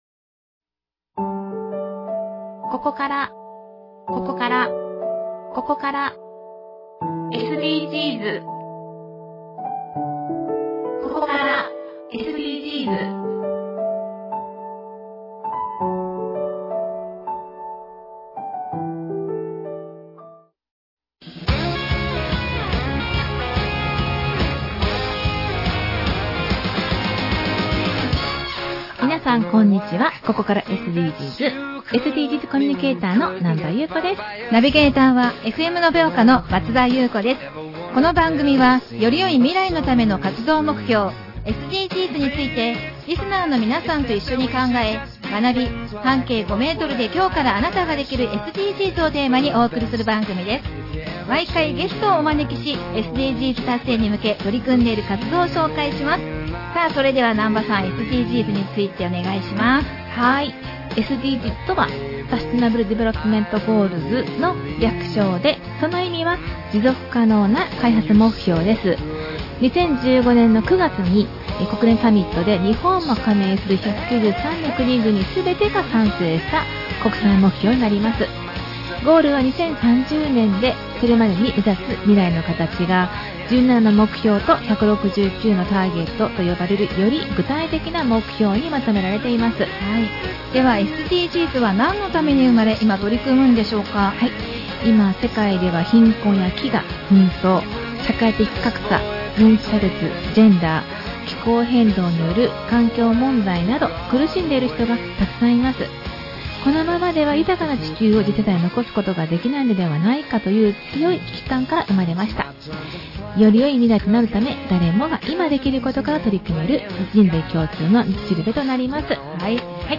特別番組